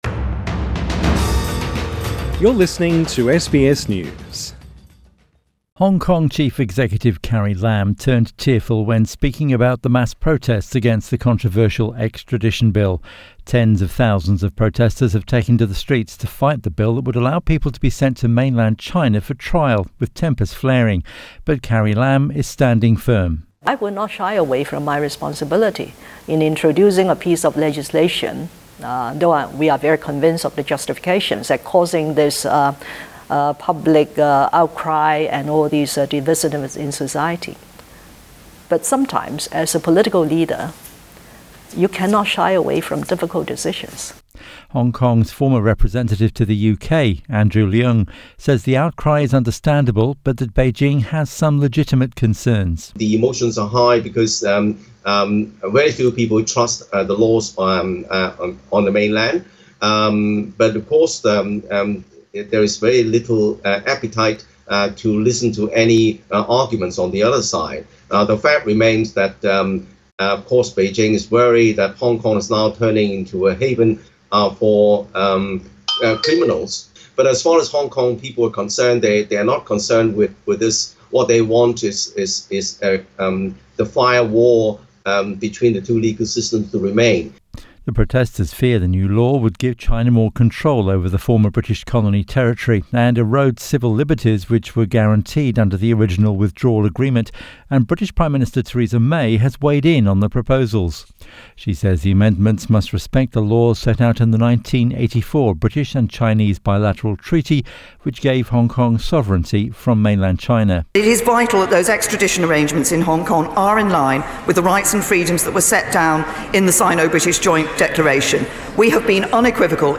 Tearful Hong Kong Chief Executive Carrie Lam says she would never do anything that is not in the interest of Hong Kong.